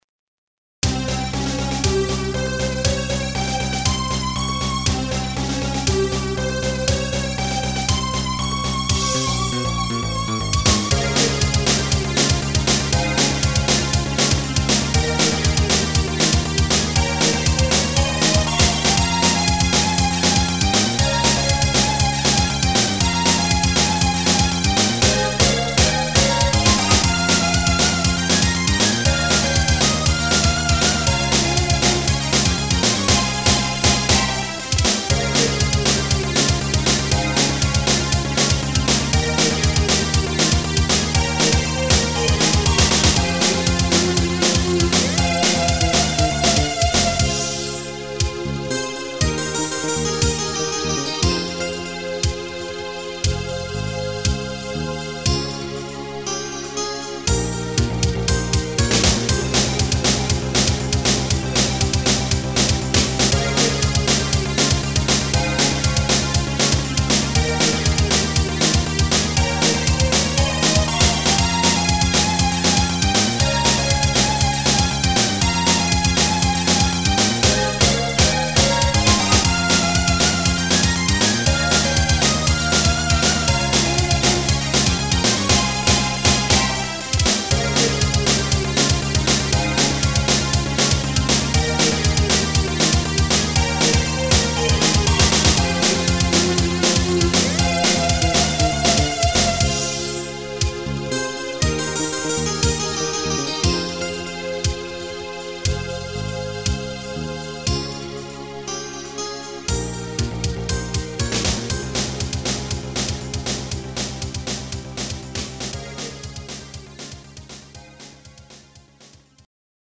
ゲーム音楽アレンジ集
随分前にFPD98を使って作ったもの。